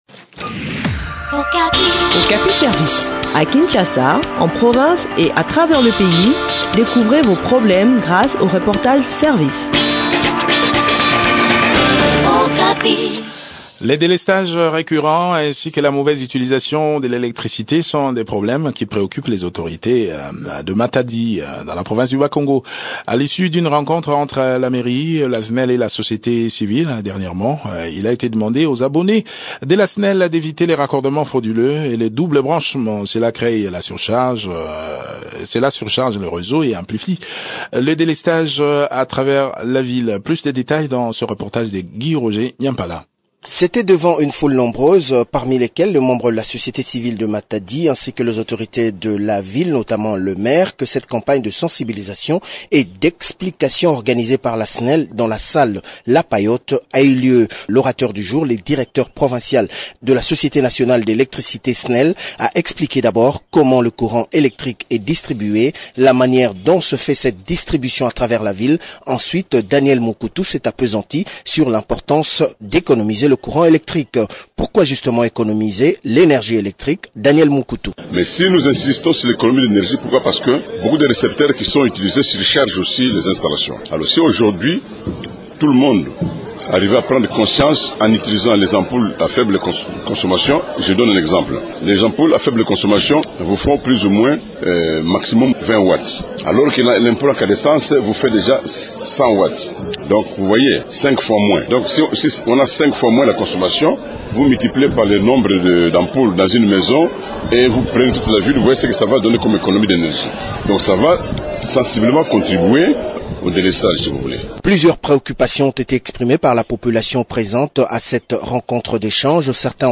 Le point sur le déroulement de cette campagne de sensibilisation dans cet entretien